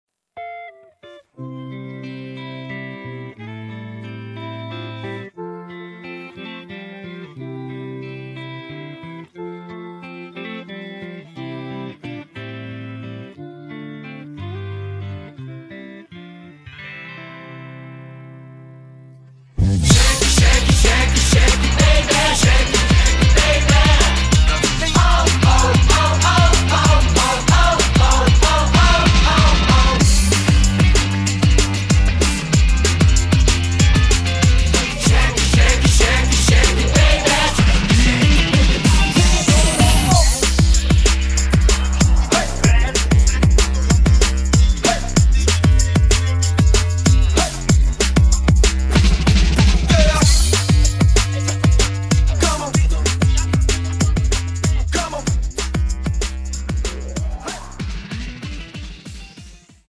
음정 원키 3:18
장르 가요 구분 Voice MR